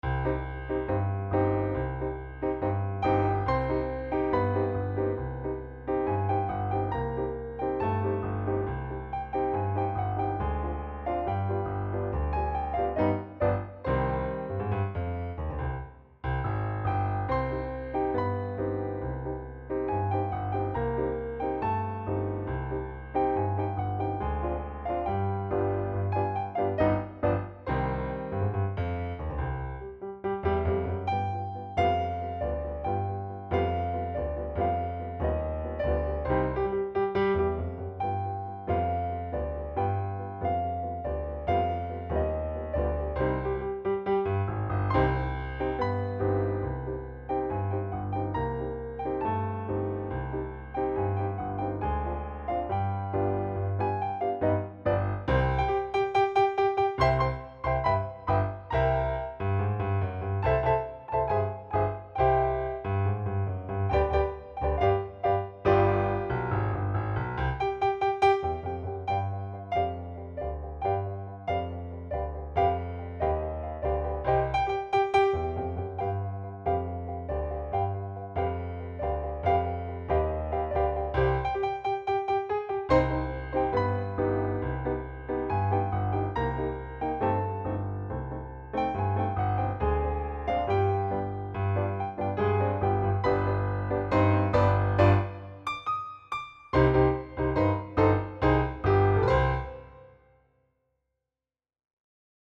Scum Tango
keyboard